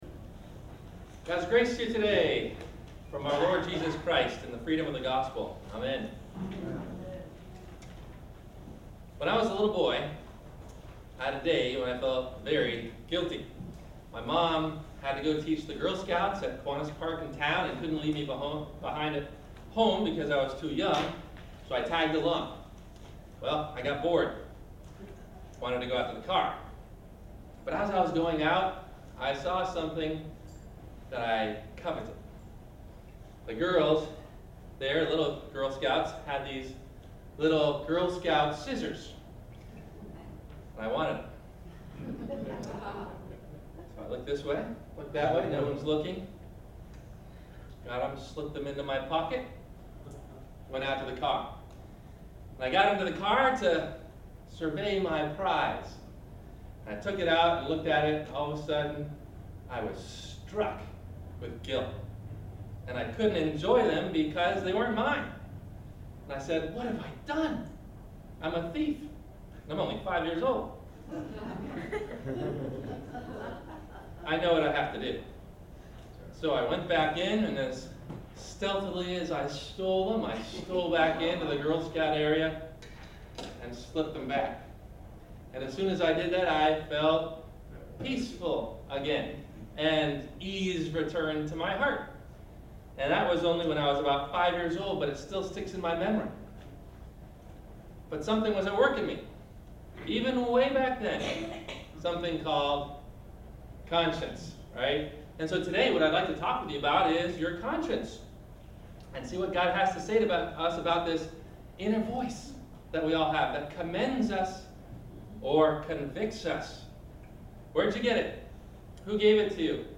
Can I Trust and Follow My Conscience? – Sermon – October 13 2013